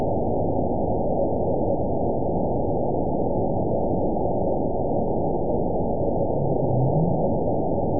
event 911031 date 02/08/22 time 10:59:12 GMT (3 years, 9 months ago) score 9.11 location TSS-AB01 detected by nrw target species NRW annotations +NRW Spectrogram: Frequency (kHz) vs. Time (s) audio not available .wav